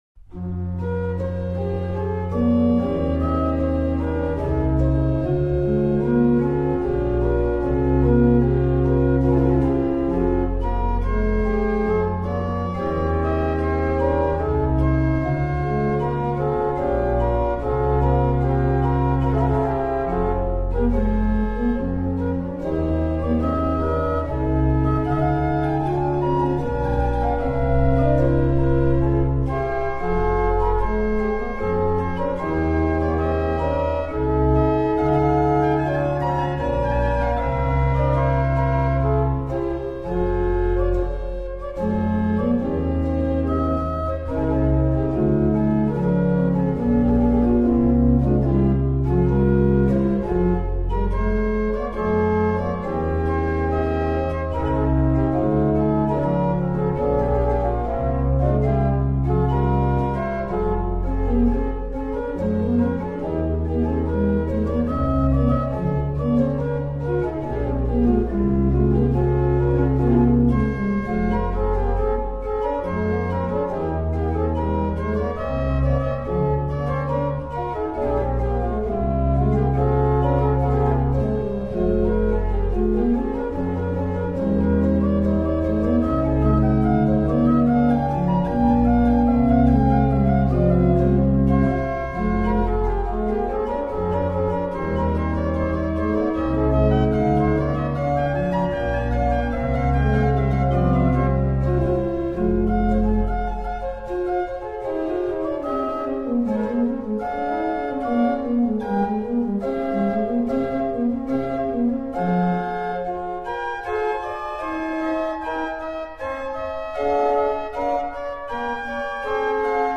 Dietrich Buxtehude [1637 -1707] Ciacona in E minor